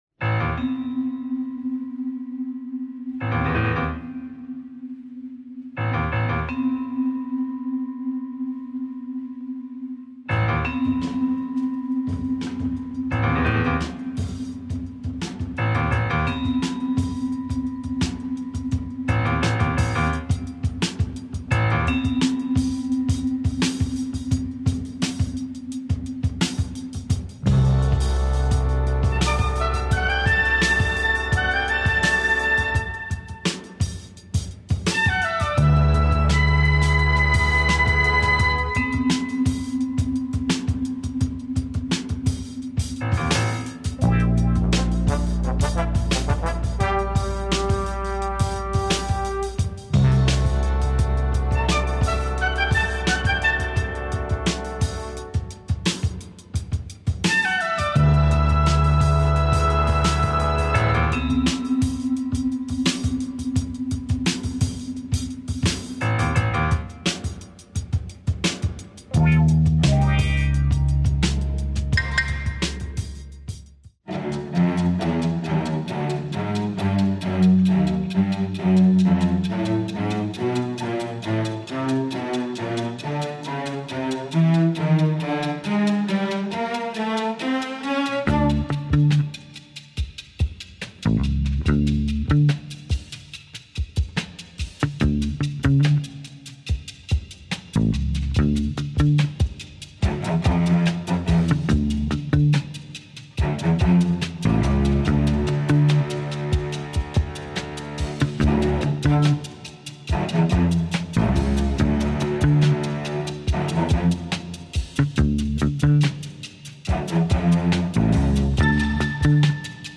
pure drama / tense groove, fat beats…perfect.